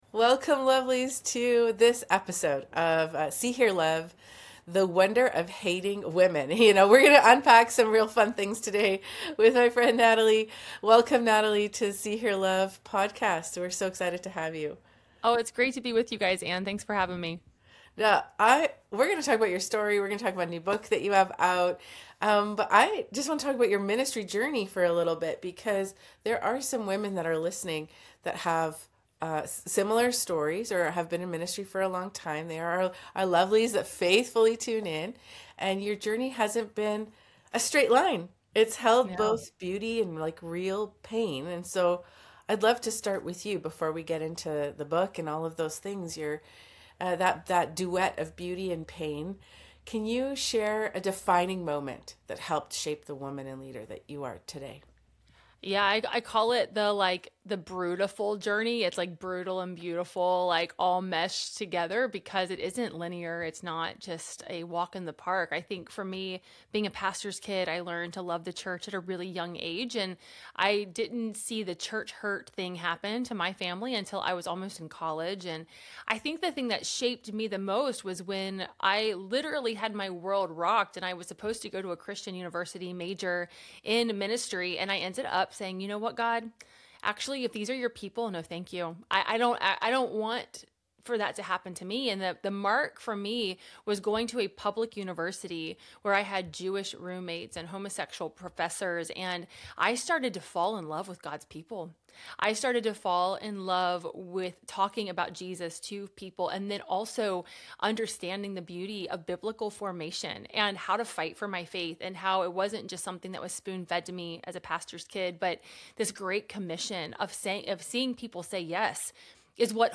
This powerful conversation is an invitation for women to reject comparison, embrace sisterhood, and become a resilient, multi-generational church rooted in Christ.